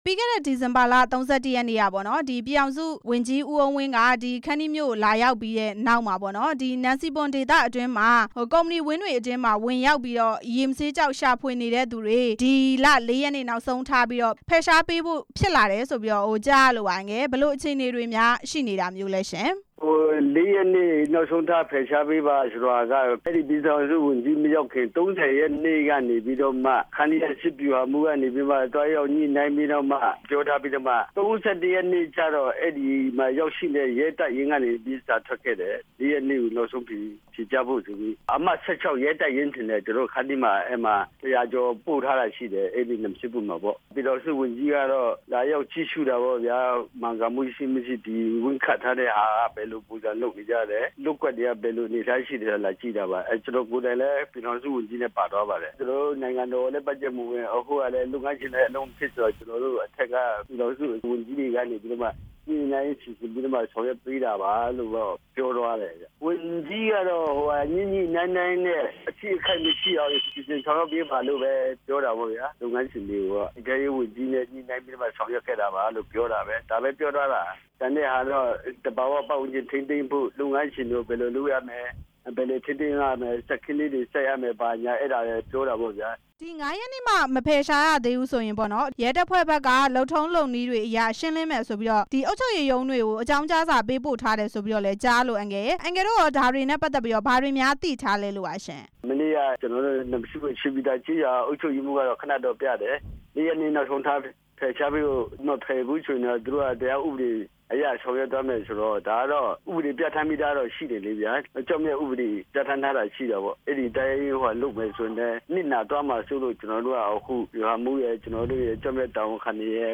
နန်စီပွန်ဒေသမှာ ရဲတပ်ဖွဲ့အင်အား ၁၀၀ ကျော်ချထားပြီး ညွှန်ကြားချက်ကို လိုက်နာမှုရှိ မရှိ စောင့်ကြည့်နေတယ်လို့ ခန္တီးမြို့နယ် ပြည်သူ့ လွှတ်တော်ကိုယ်စားလှယ် ဦးအောင်သန်းစိန် က ပြောပါတယ်။